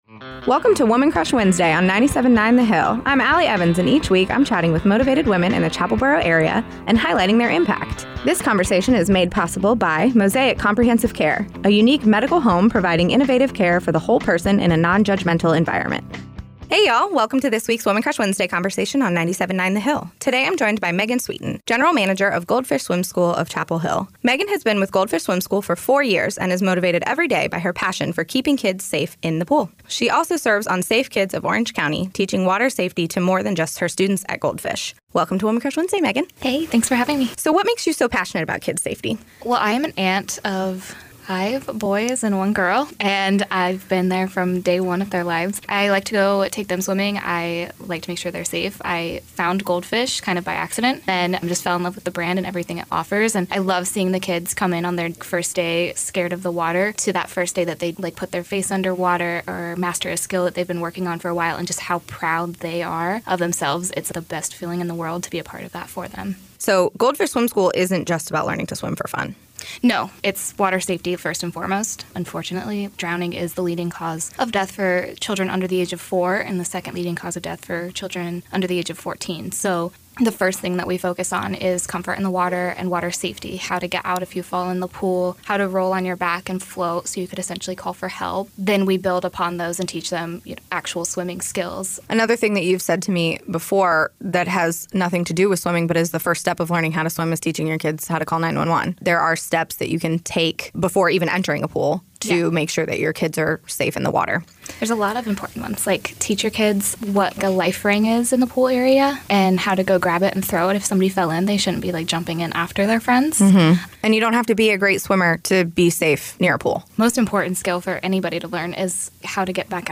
a three-minute weekly recurring segment